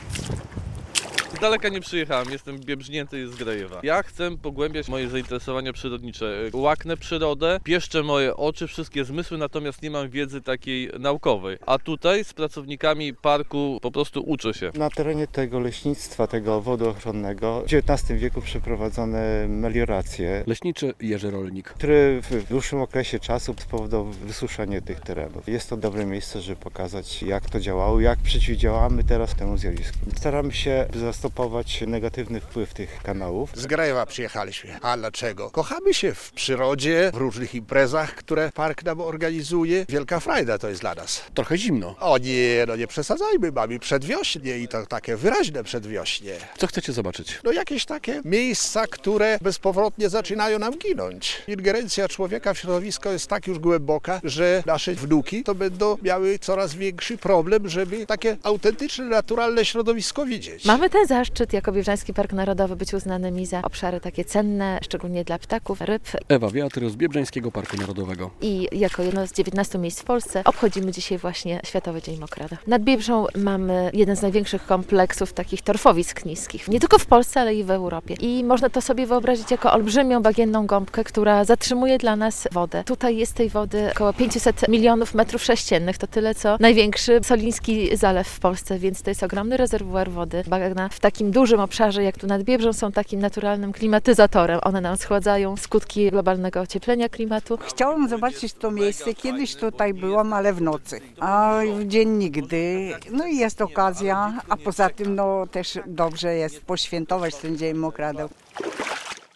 Światowy Dzień Mokradeł w Biebrzańskim Parku Narodowym - relacja